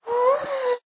moan4.ogg